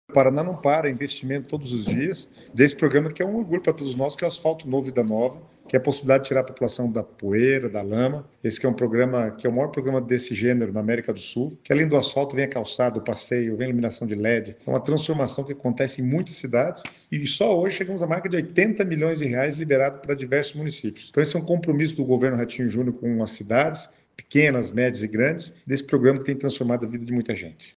Sonora do secretário das Cidades, Guto Silva, sobre a liberação de 81,7 milhões para obras em nove municípios